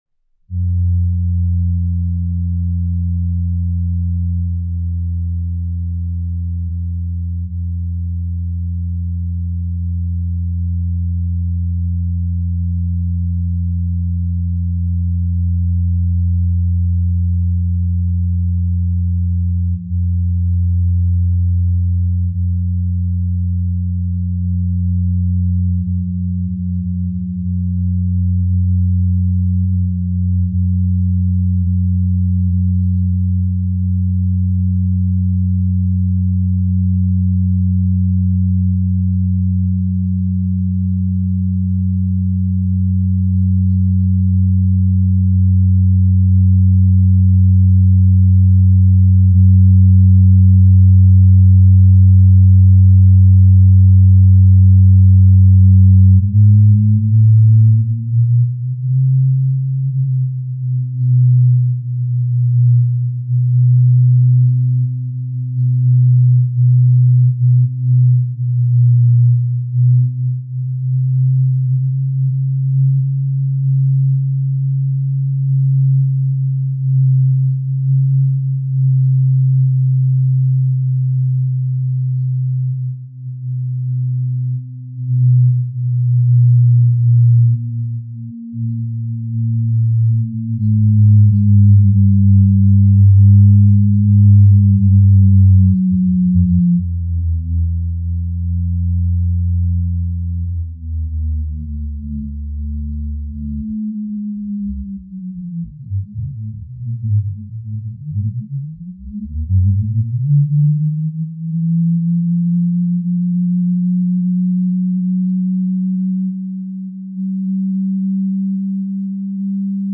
Nach 3 Tagen ändert sich die Tonspur:
2. Mensch und Brot (Überlagerung von Brot und Brahms Requiem)
3. Tonale Brotspuren aus dem Brotumfeld